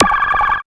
5206L SCI-FI.wav